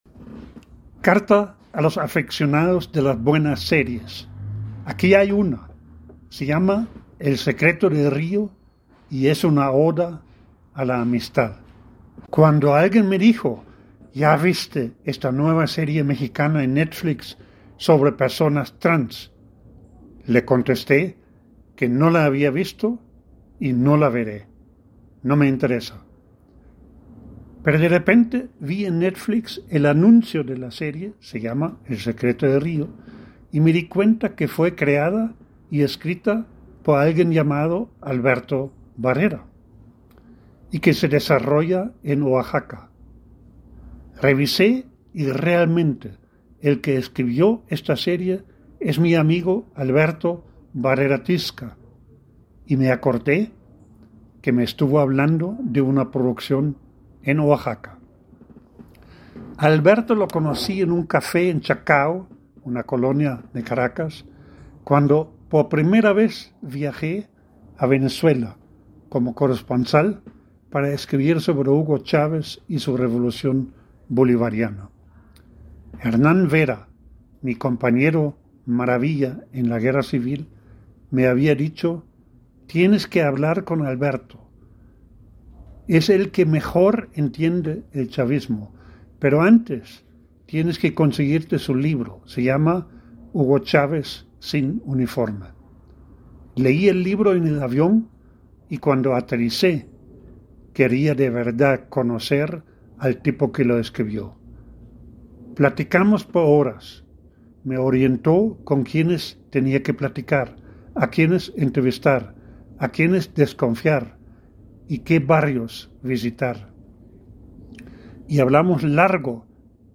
El audio en la voz del autor: